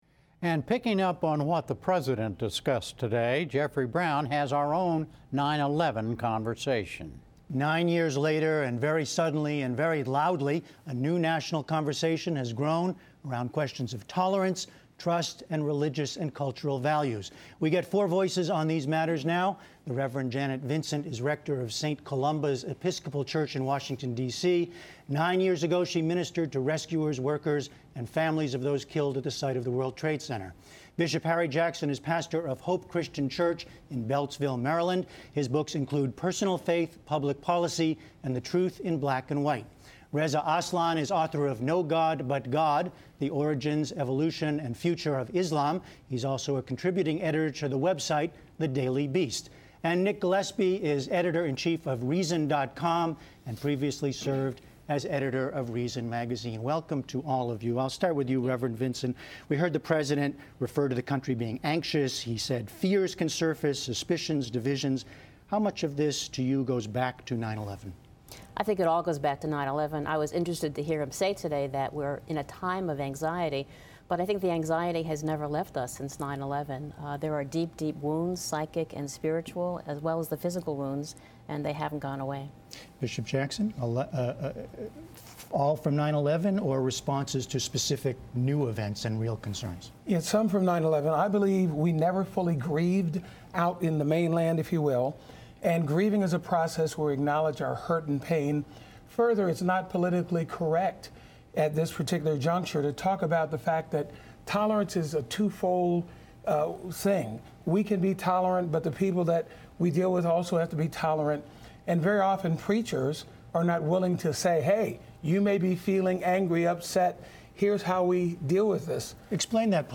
Nick Glilespie appeared on the PBS NewsHour as part of a panel discussing: 9 Years After 9/11, Has Religious Tolerance Changed in America? Jeffrey Brown moderates a conversation among four religious leaders and experts on the tolerance -- or intolerance -- of different religions and cultures in America, nine years after the Sept. 11 attacks.